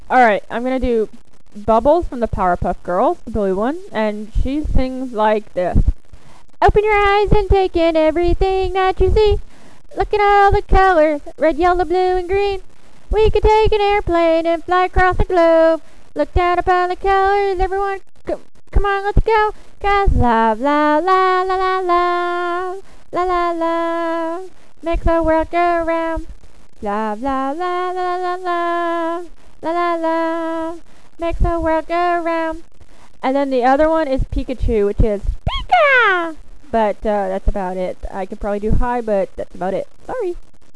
Here are a list of Accents and Impersonations I can do...
Bubbles Powerpuff Girl and Pikachu